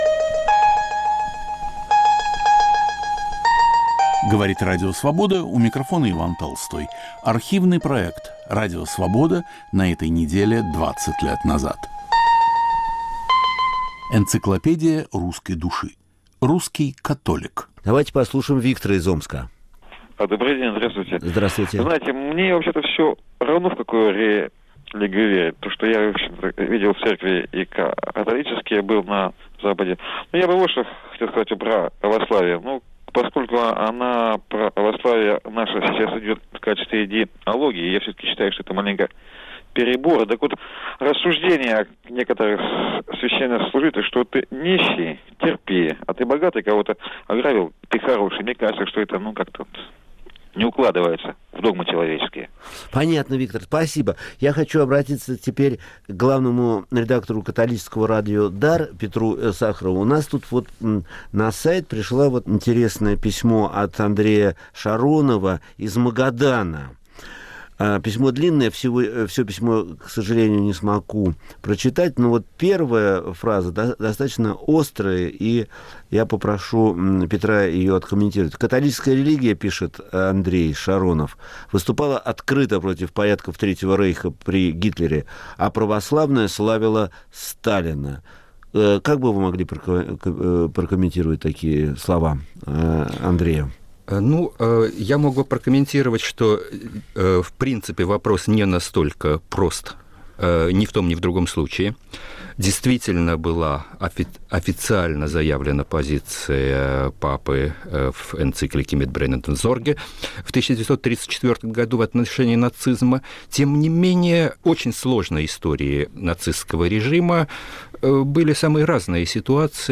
Отношение православия к католичеству. Автор и ведущий Виктор Ерофеев.